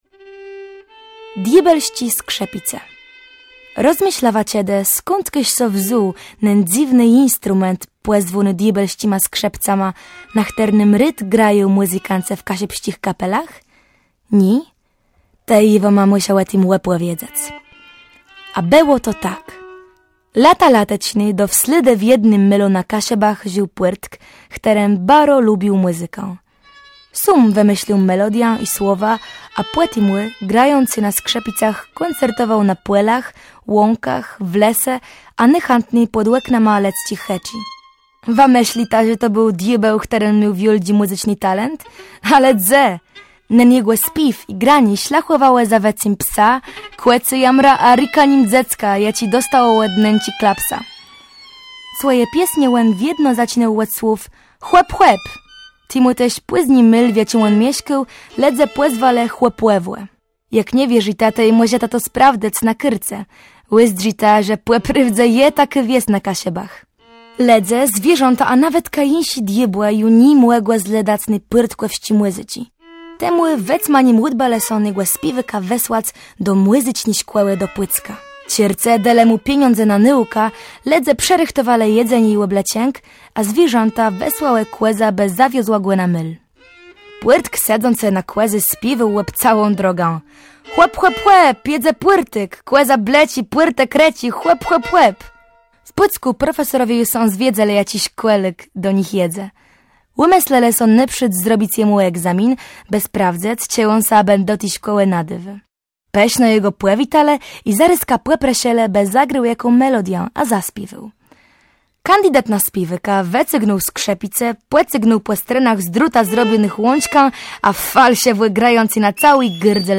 Nagrań dokonano w Radiu Gdańsk.
Oprawą muzyczną audiobooka zajęła się formacja Almost Jazz Group.
Posłuchaj Diôbelsczé skrzëpice (Roman Drzeżdżon) czyta piosenkarka Natalia Szroeder